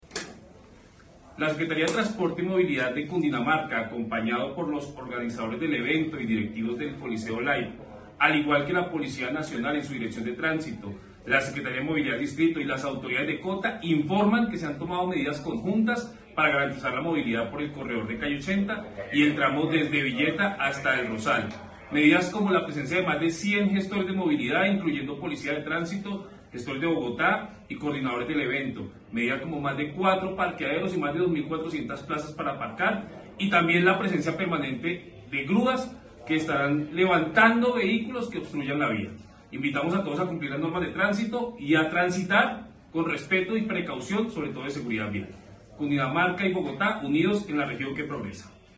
Declaración del secretario de Transporte y Movilidad de Cundinamarca.